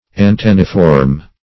\An*ten"ni*form\
antenniform.mp3